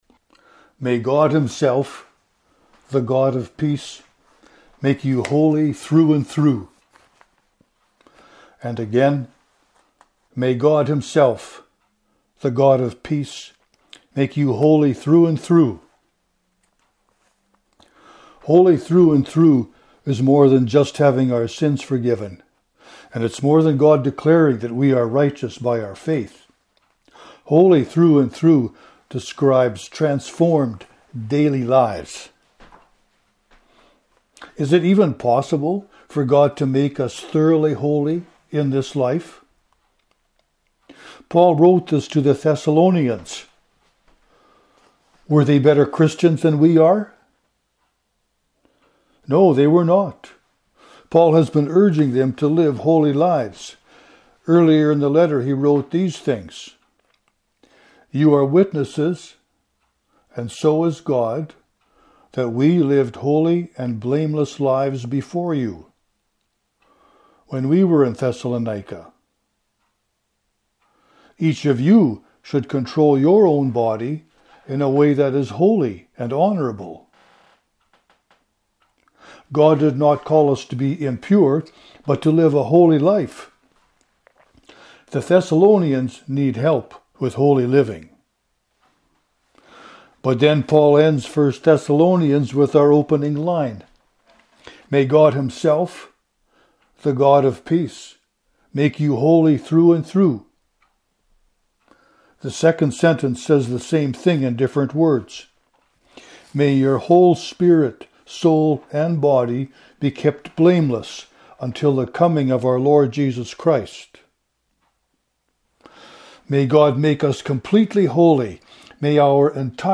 (KCC, Providence 100th Service)